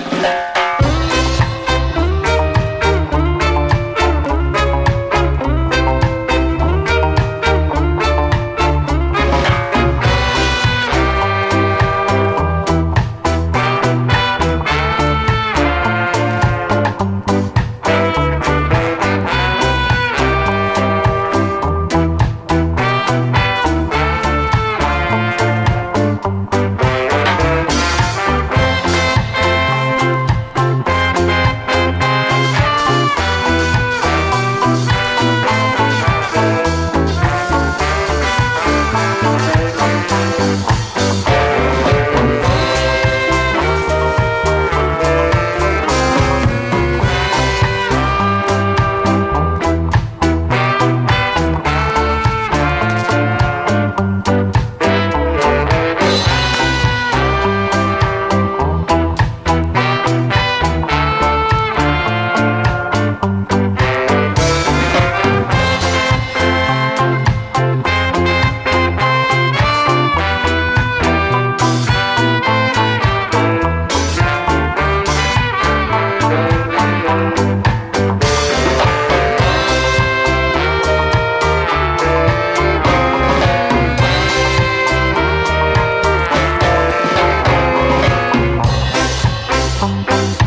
哀愁ロックステディ〜オルガンがはしゃぎたてる王道スキンヘッド・レゲエ・サウンド！
ドラムス
ベース
ギター
オルガン
ピアノ
トランペット
テナーサックス
トロンボーン